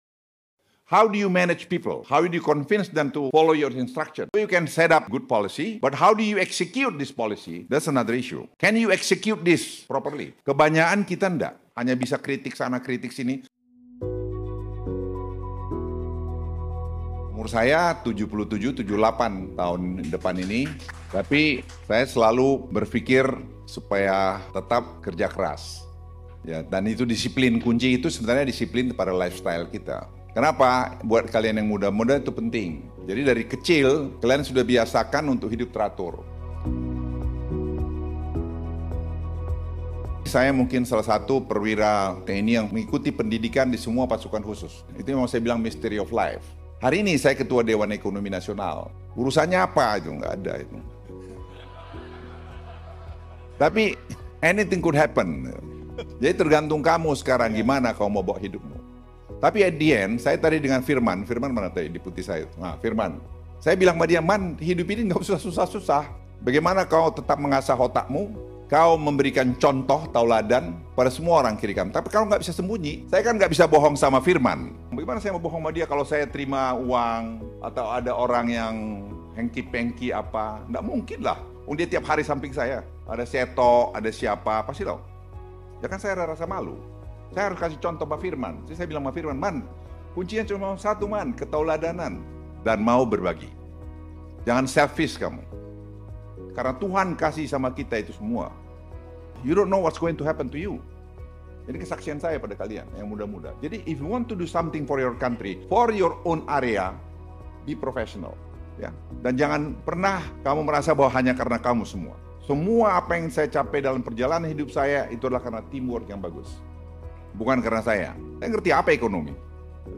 POWERFUL SPEECH